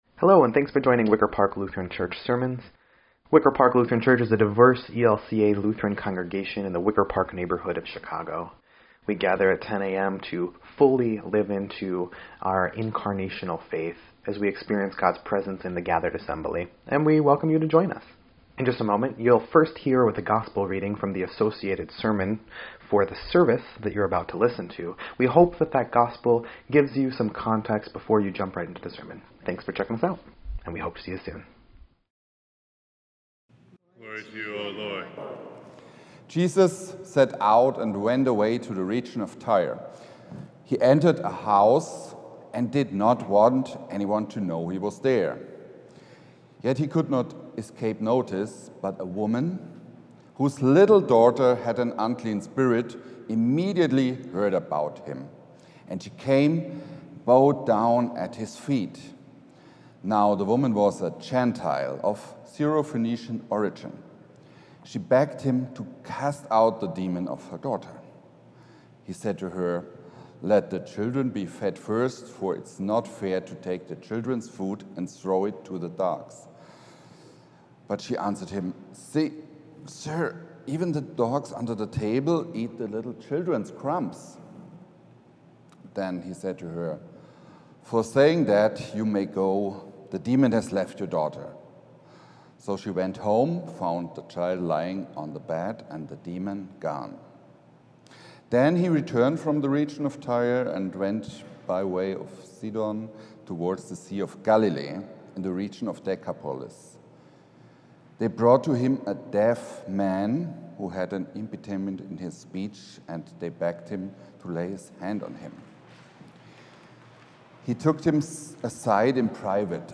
Sixteenth Sunday after Pentecost
9.8.24-Sermon_EDIT.mp3